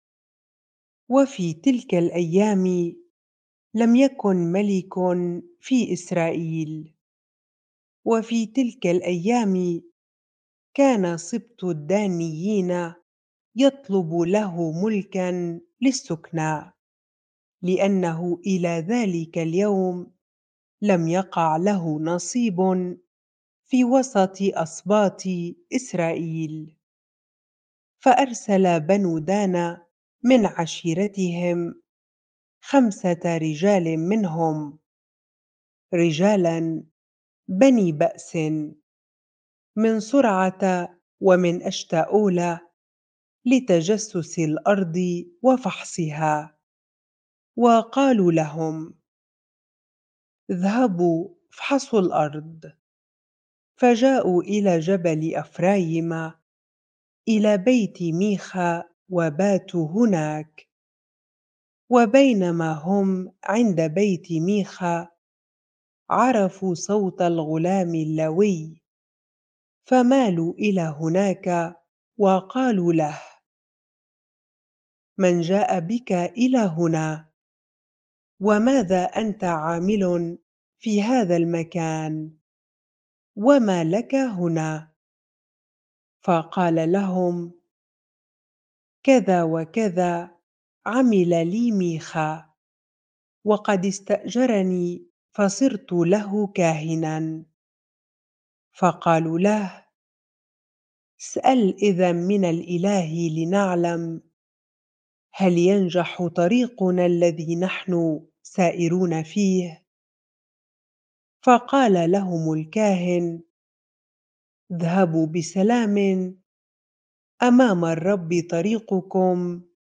bible-reading-Judges 18 ar